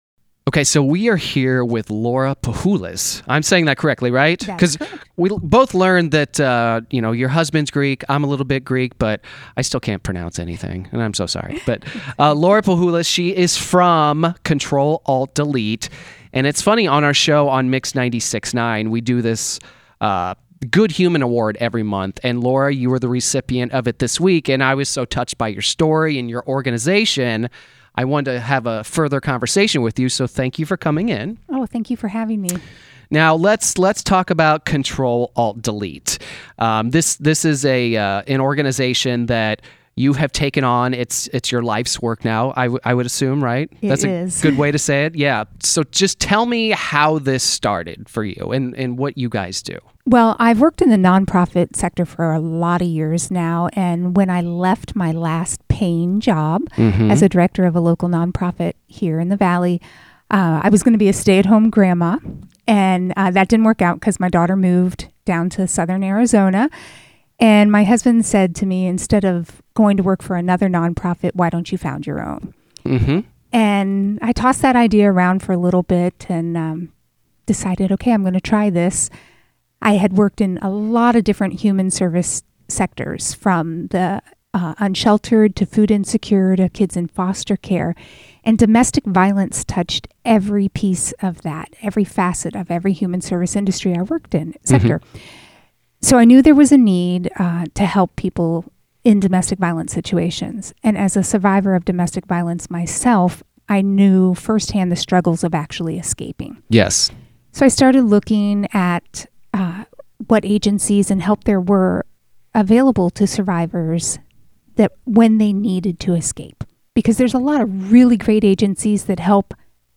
Mix 96.9 Interview with Control Alt Delete - Control Alt Delete